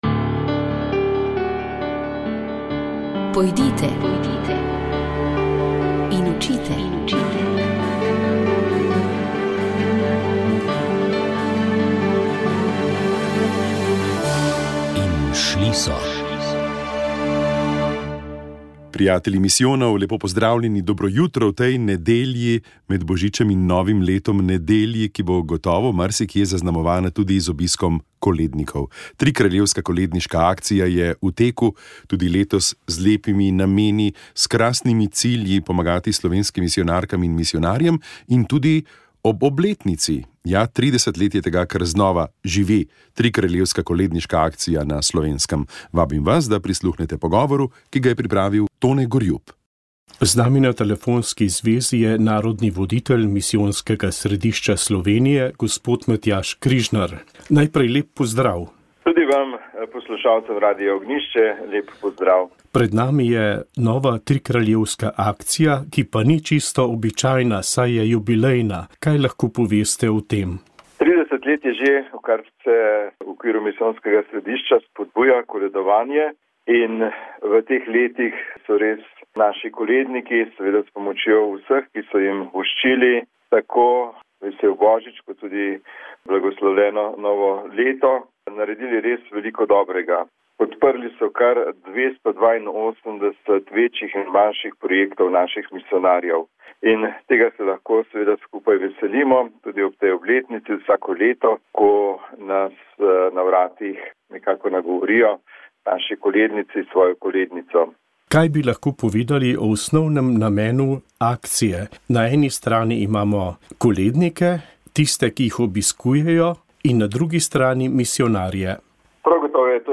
Septembra je v 17-ih dneh prehodil 680 km s približno 38.000 metri vzpona in spusta. Kakšen je bil njegov namen, kaj je doživel in kako premagoval težave, ki jih je prinesel ta ekstremni podvig in tudi kakšne ima načrte za naslednje leto, je zaupal v sproščenem pogovoru.